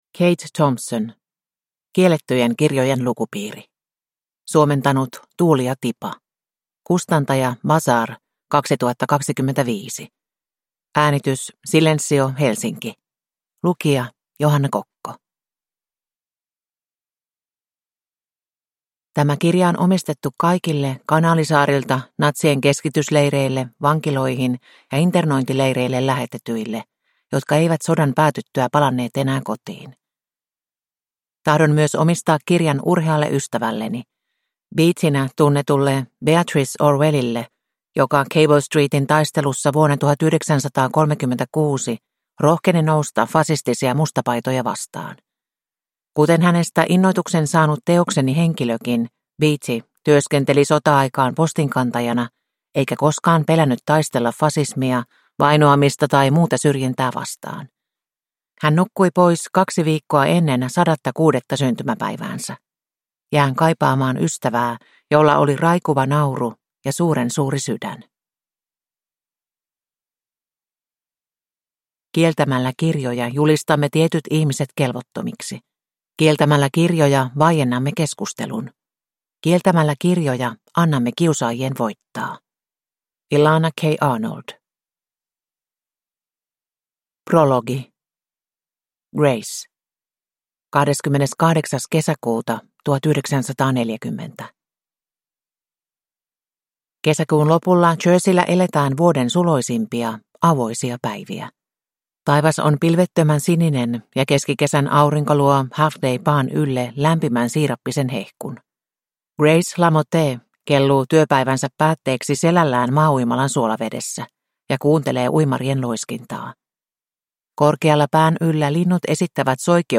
Kiellettyjen kirjojen lukupiiri (ljudbok) av Kate Thompson